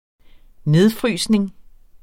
Udtale [ ˈneðˌfʁyːˀsnəŋ ]